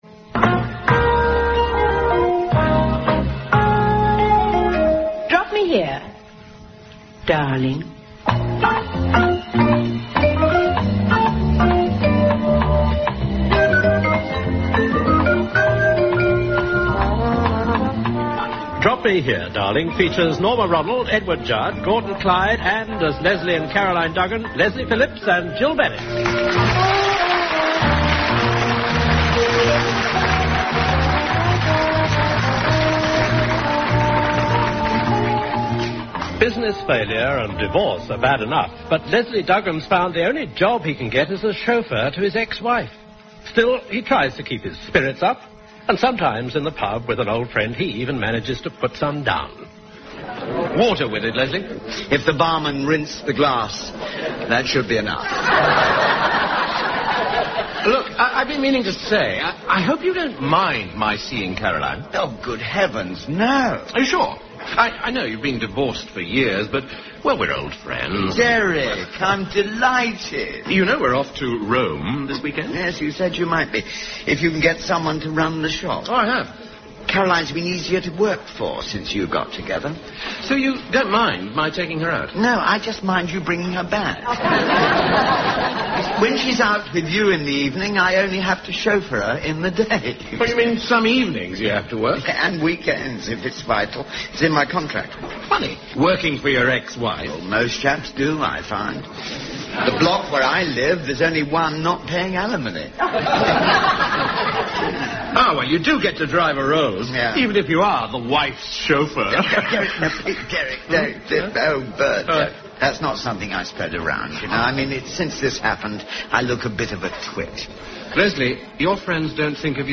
1980s British radio sitcom starring Leslie Phillips